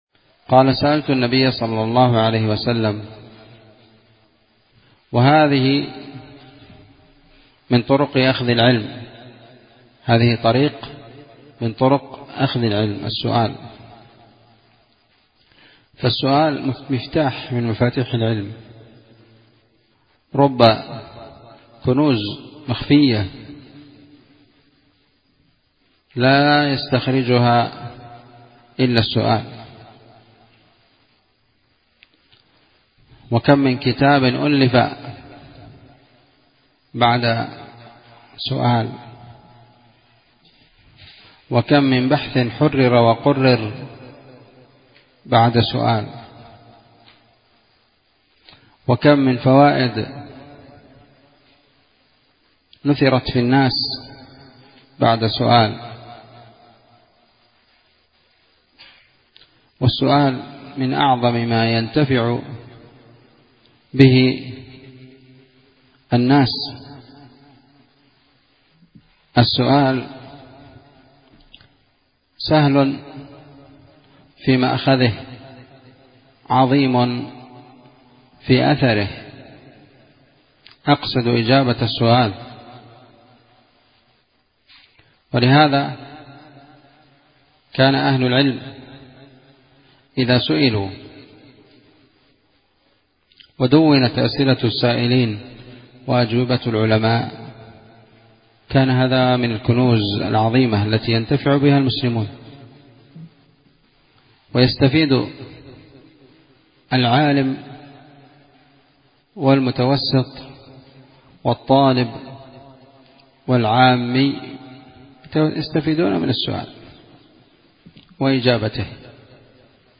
نصيحة طيبة ومقتطف جميل من درس عمدة الأحكام